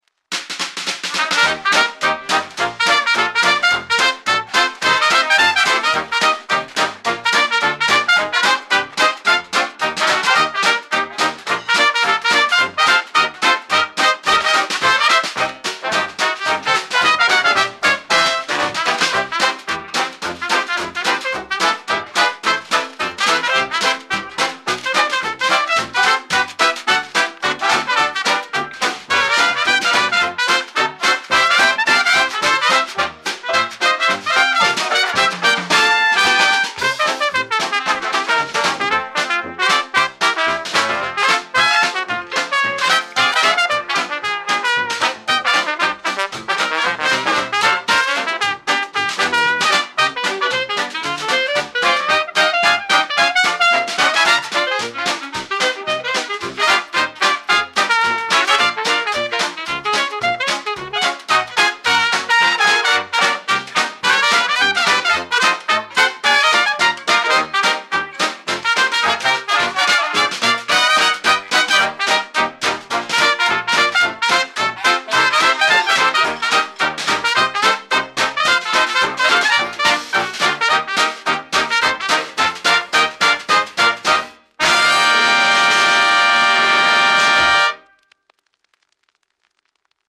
New Orleans jazz funeral second line with joyful brass and snare drum